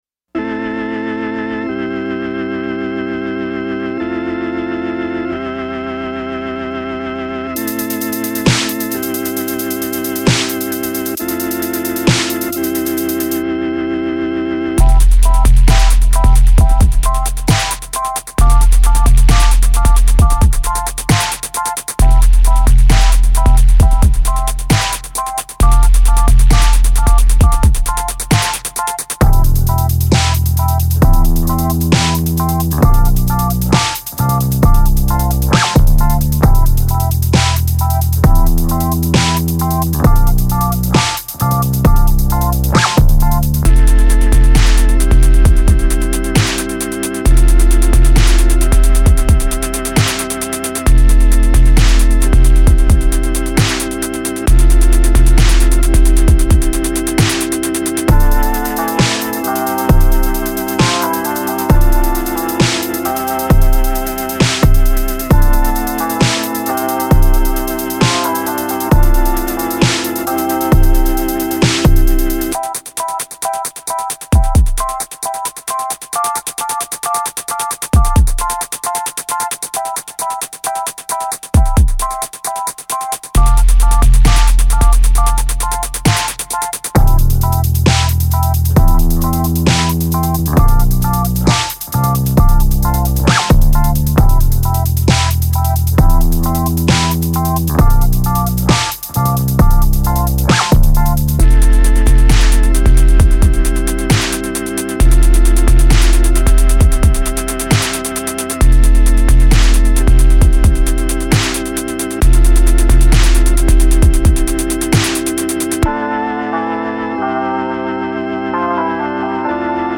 14 new instrumentals for you to rock over.